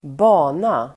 Uttal: [²b'a:na]